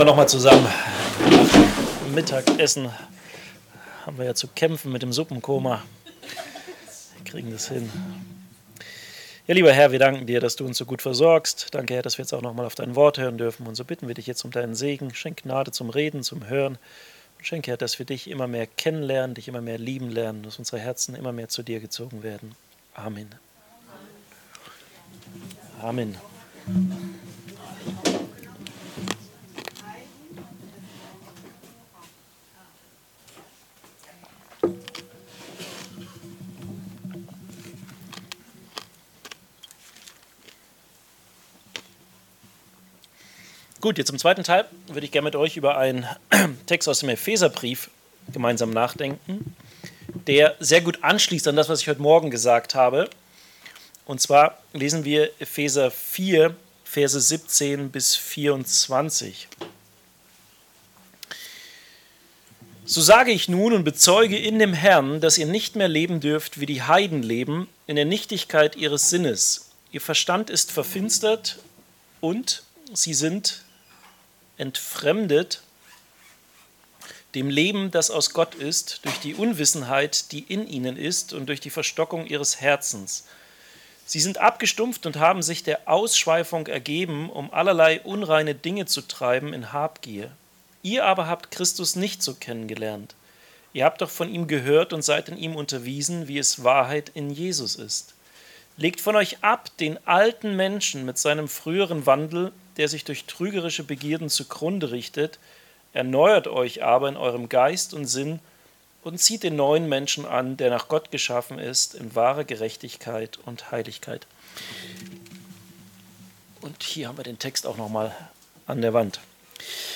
MP3 Predigten